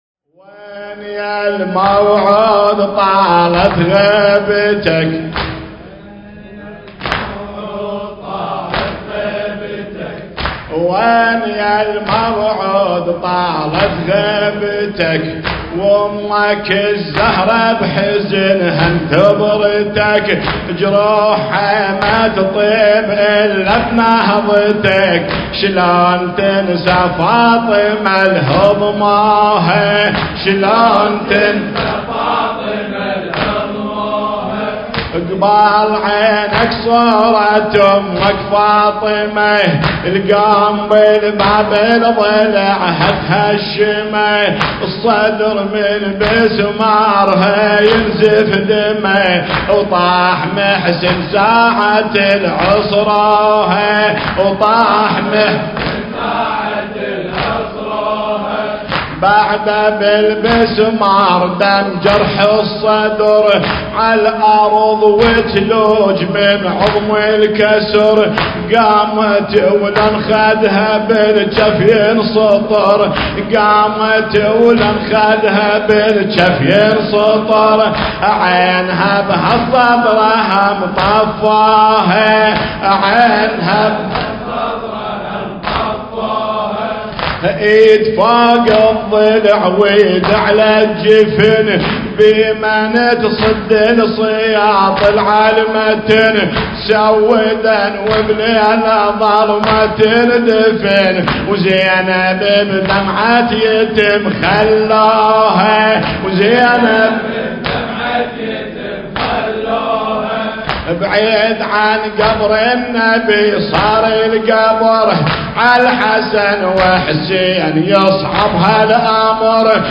شهادة الإمام علي عليه السلام - ١٤٣٤هجرية المكان: حسينية آل ياسين بدولة الكويت